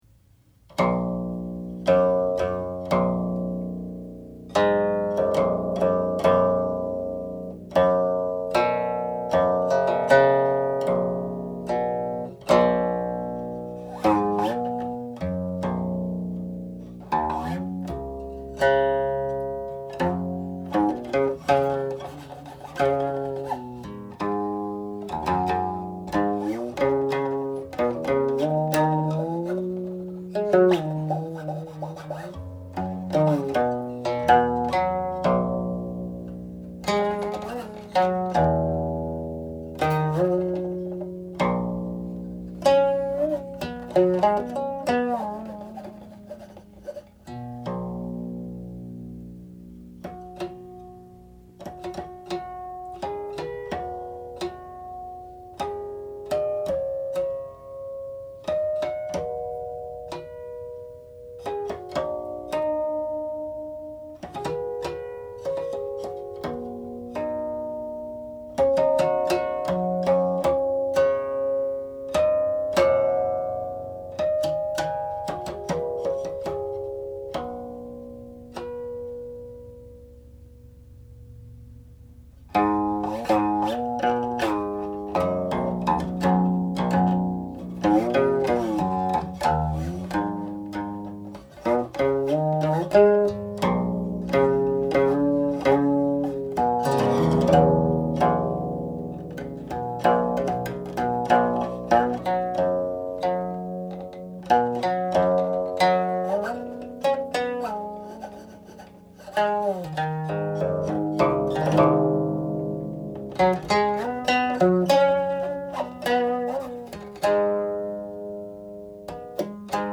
It has beautiful sounds, like the phrases "scattered shadows" and "subtle scent" (from Lin Bu's poem).
06.35     closing harmonics